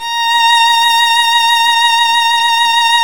Index of /90_sSampleCDs/Roland L-CD702/VOL-1/STR_Violin 1-3vb/STR_Vln2 % marc
STR  VL A#6.wav